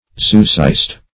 Search Result for " zoocyst" : The Collaborative International Dictionary of English v.0.48: Zoocyst \Zo"o*cyst\, n. [Zoo- + cyst.]